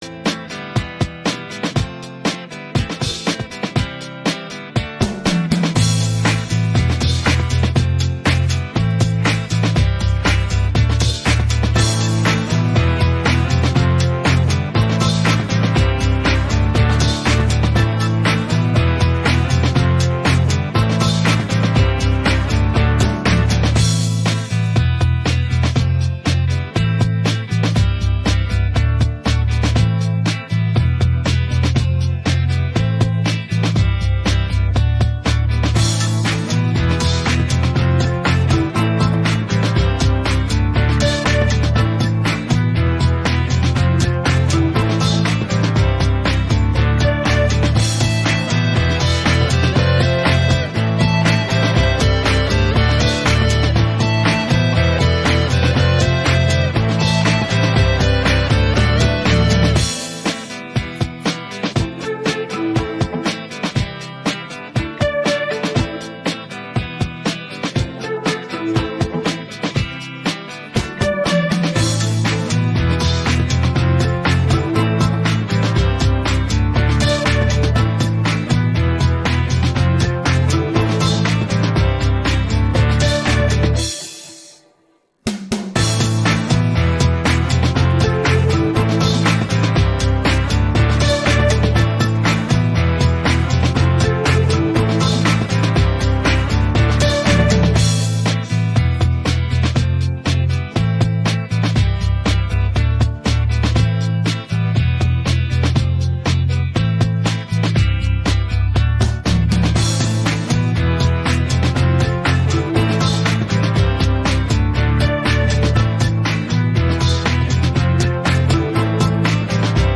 pop rock караоке